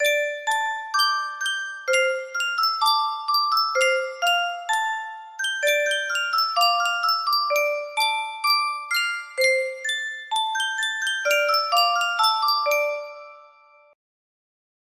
Yunsheng Music Box - Ai Ai Bopem 4590 music box melody
Full range 60